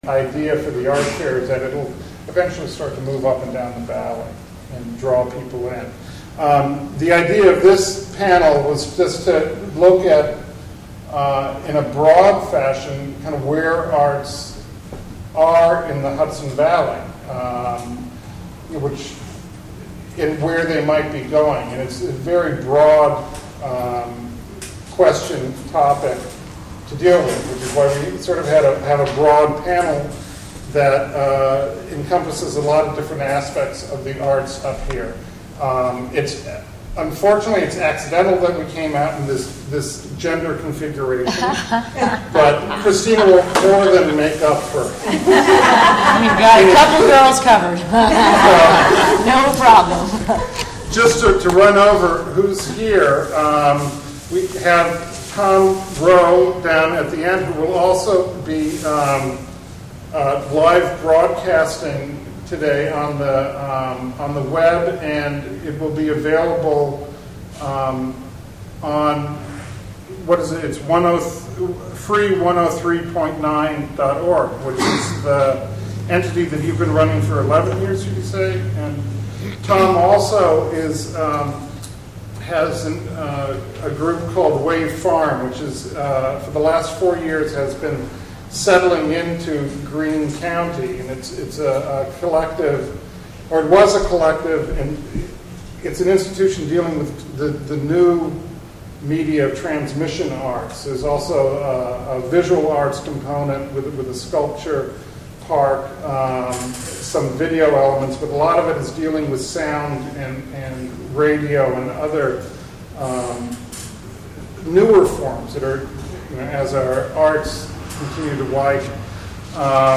Panel on the Present and Future of Arts in the Hudson Valley (Audio)